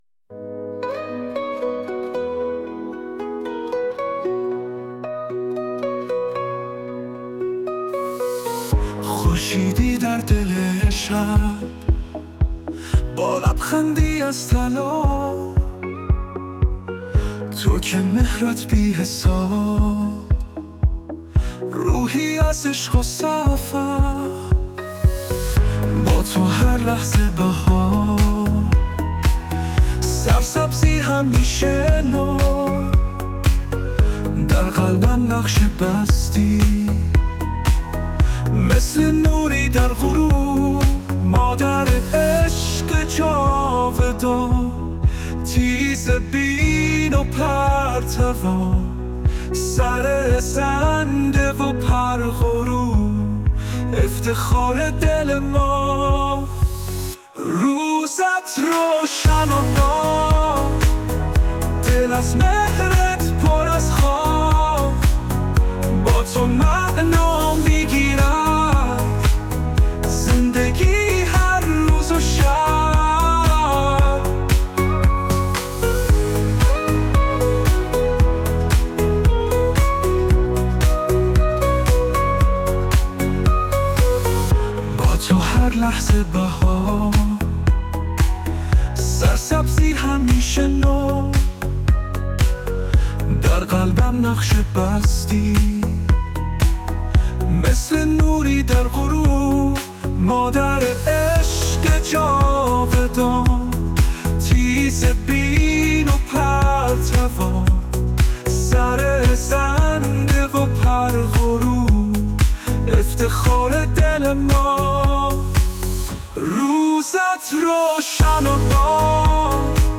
برچسب: روز مادر شعر ادبی آهنگ شاد